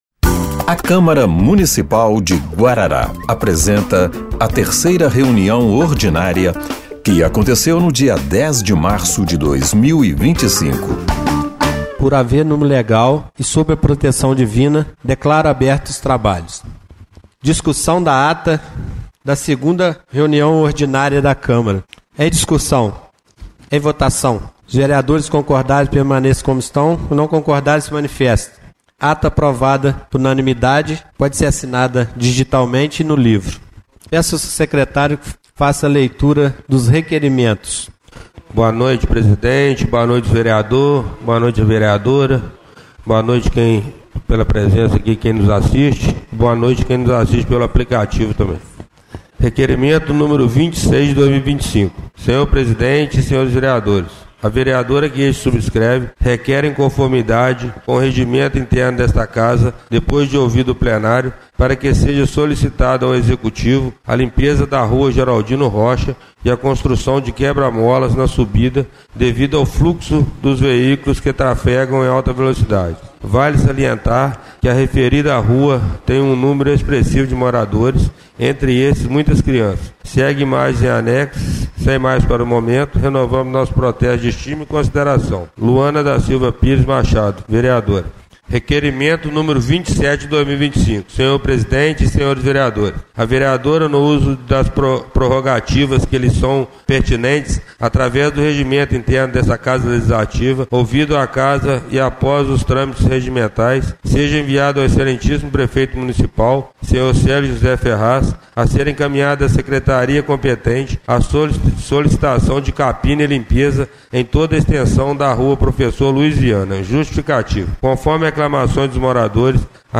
3ª Reunião Ordinária de 10/03/2025